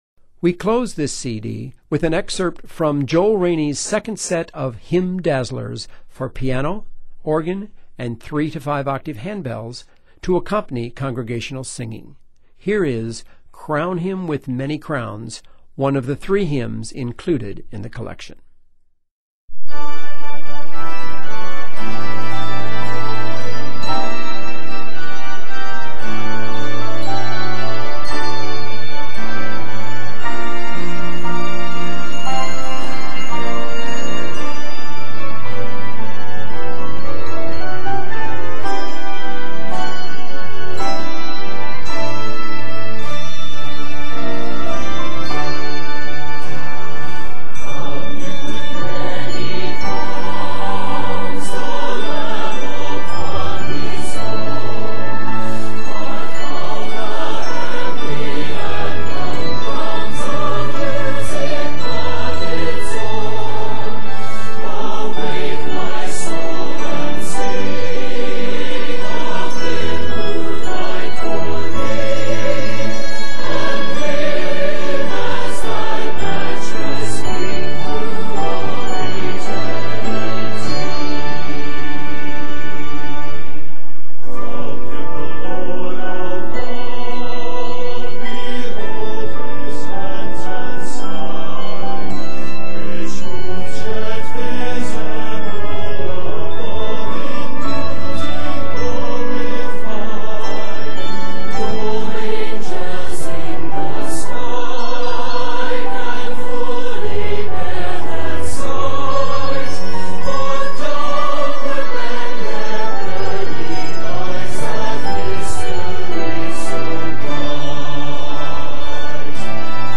Each hymn includes simple handbell enhancements.
Octaves: 3-5